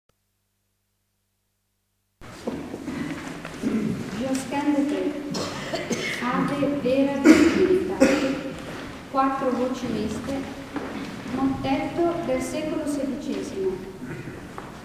Concerto dell'Epifania
Chiesa di San Giorgio